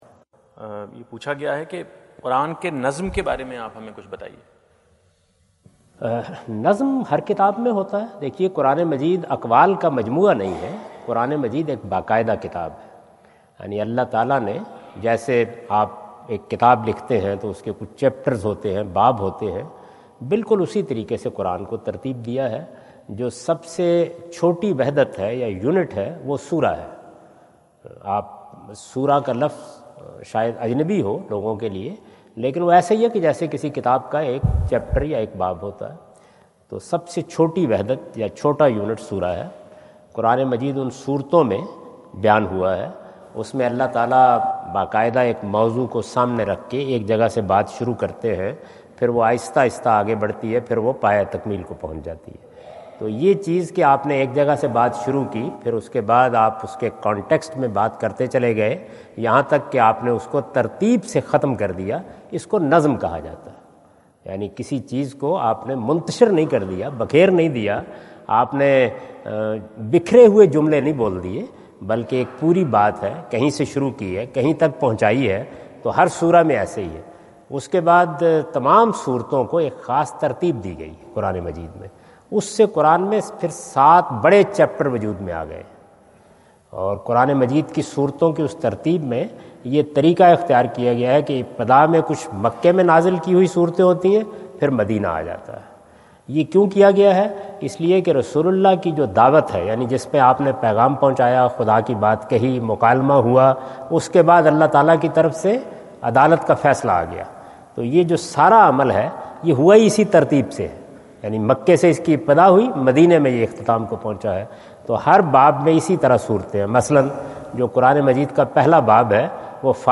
Category: English Subtitled / Questions_Answers /
Javed Ahmad Ghamidi answer the question about "What is Nazm e Quran?" During his US visit at Wentz Concert Hall, Chicago on September 23,2017.